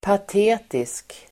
Uttal: [pat'e:tisk]